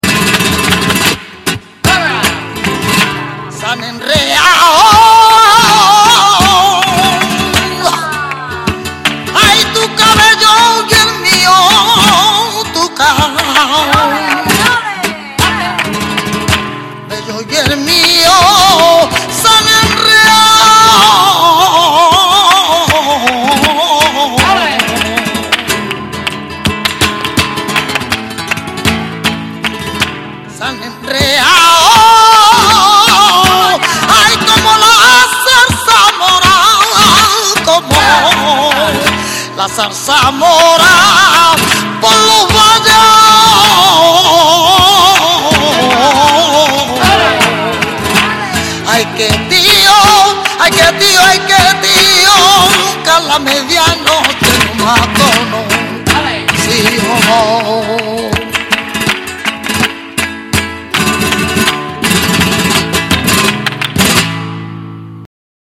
guitarra: